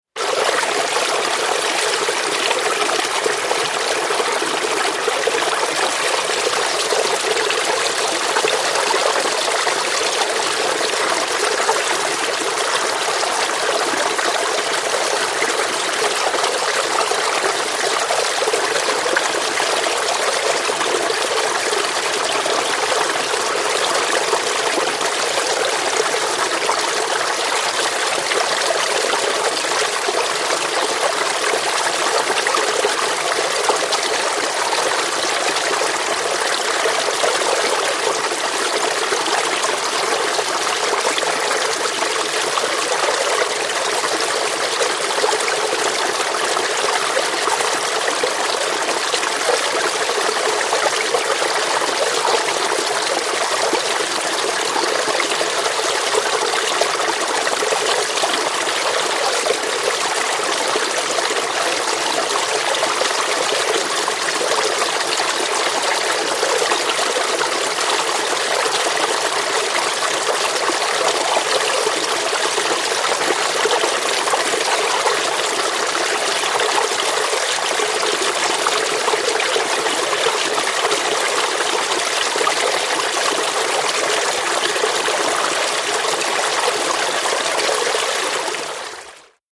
CAVES & TUNNELS STREAM: Gurgling through small rock cavern, close up.